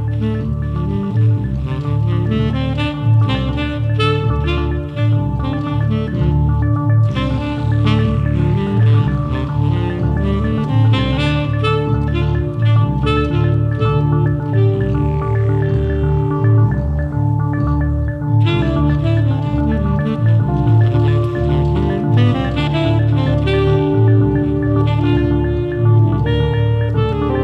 Electro RIngtones